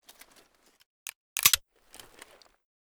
w99_unjam_empty.ogg